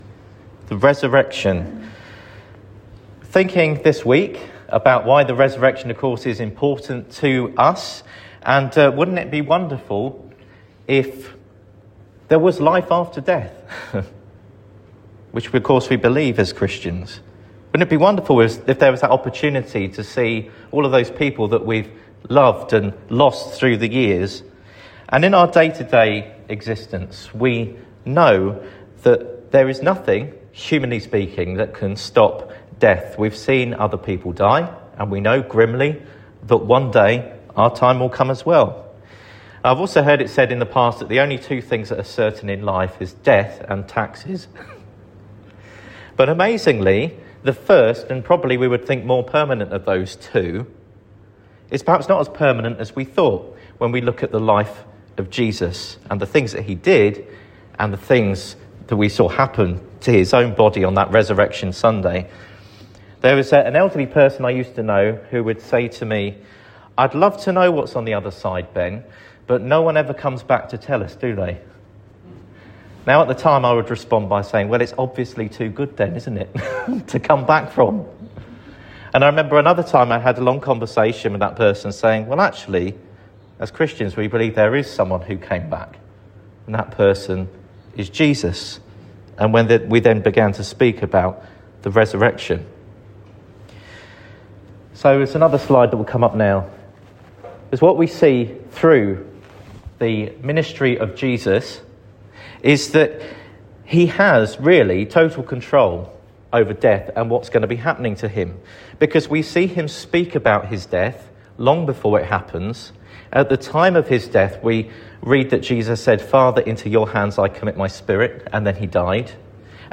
Sermon for Sunday 8th February 2026